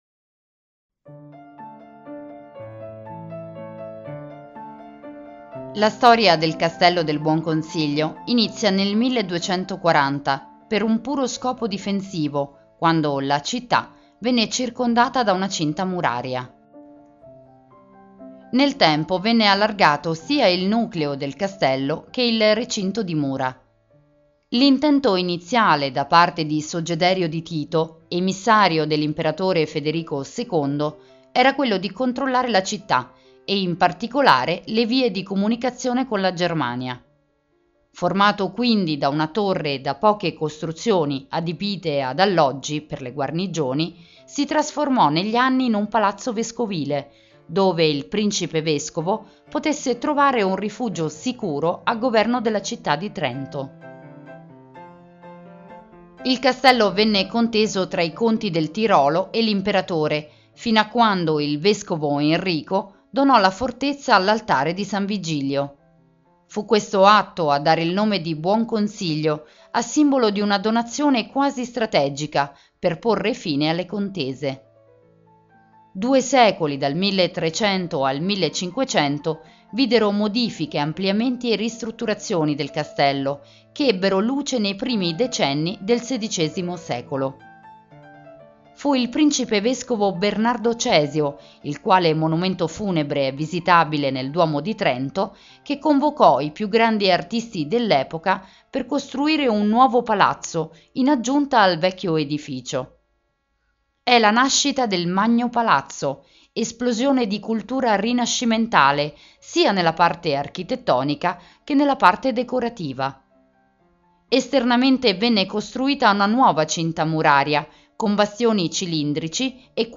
Audioguida Trento – Castello del Buonconsiglio